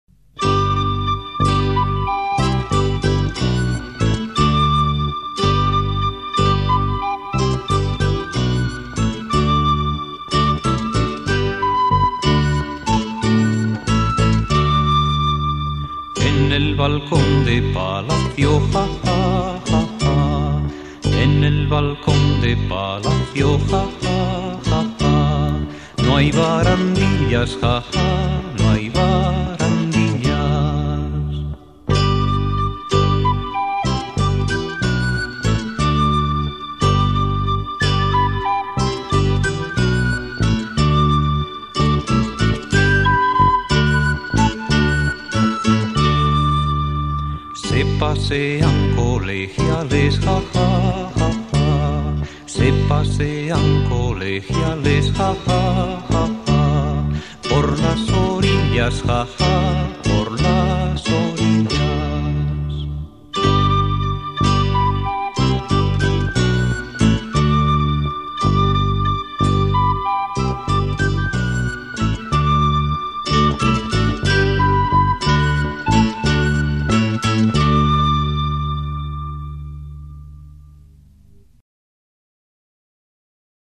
Guitarra
Bajo